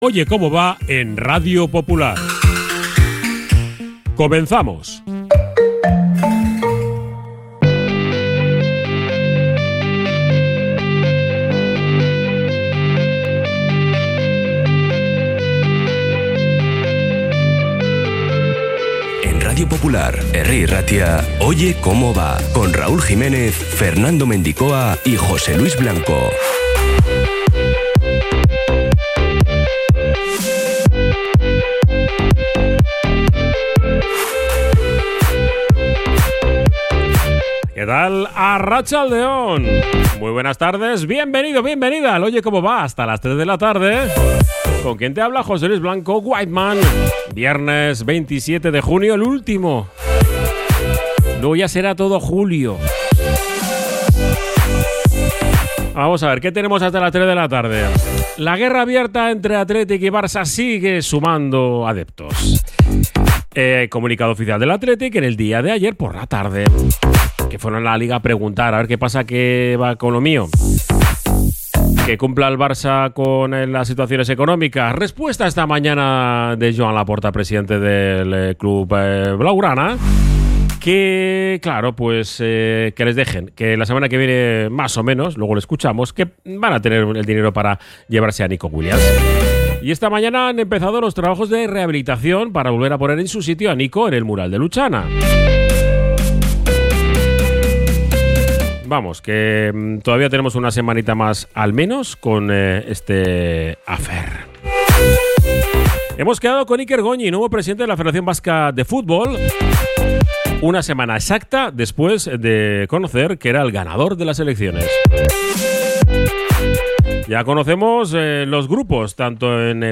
información deportiva.